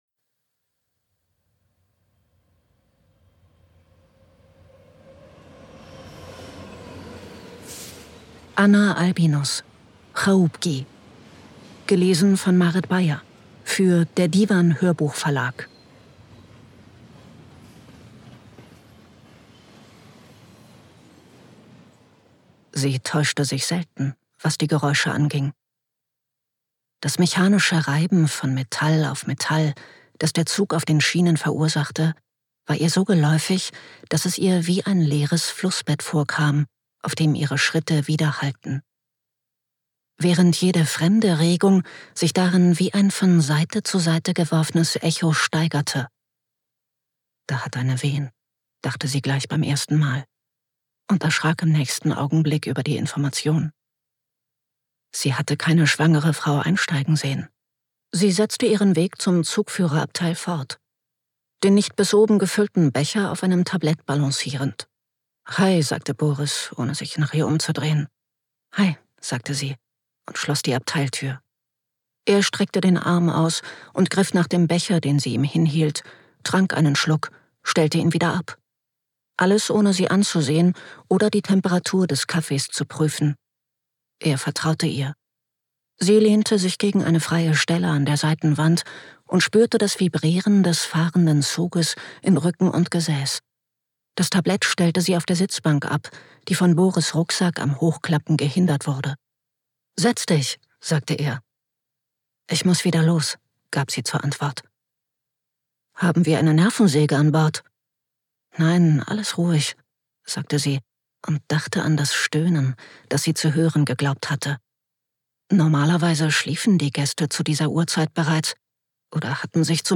Frédéric Chopin (Komponist)
Solist (Instrument): Rubenstein Arthur